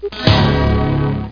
1 channel
chord3.mp3